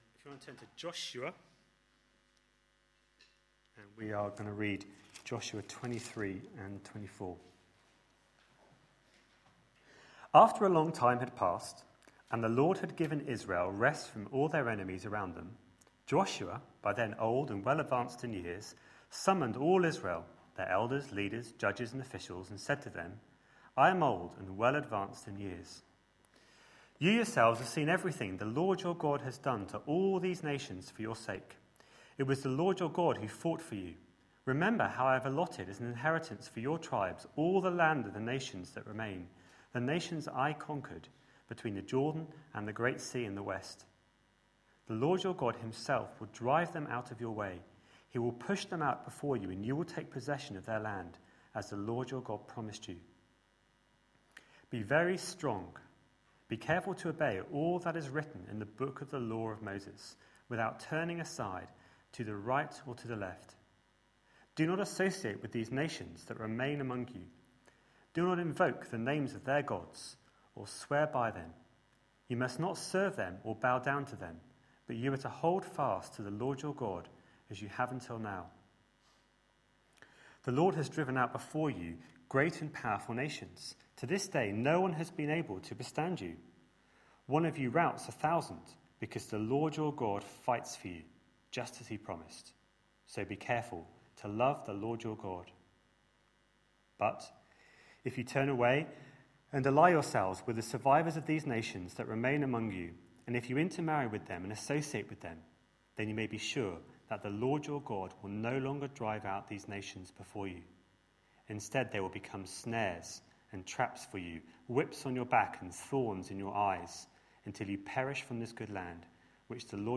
A sermon preached on 15th July, 2012, as part of our Entering God's Rest series.